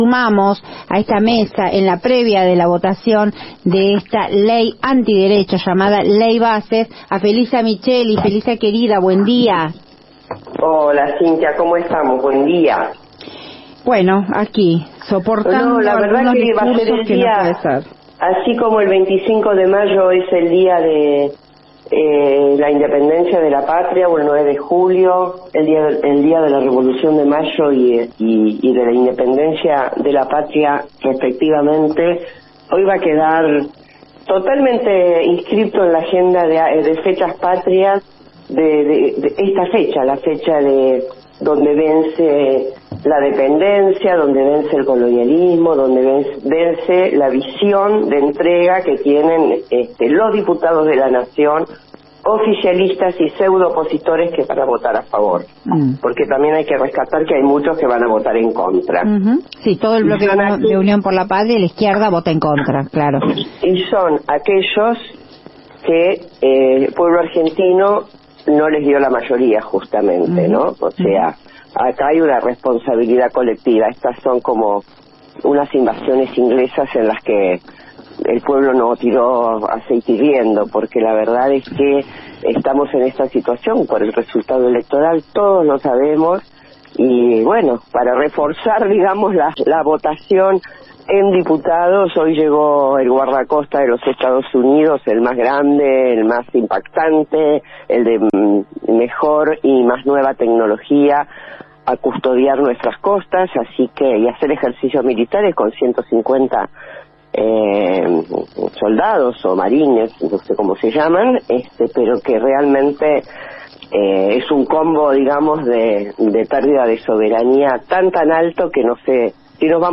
La ex Ministra de Economía en su columna semanal, analizo el tratamiento de esta ley anti derechos, llamada Ley Bases. Cuestiono el rol de Pichetto, y explicó que es el RIGI (Régimen de Incentivo para Grandes Inversiones), incluido en la Ley Bases.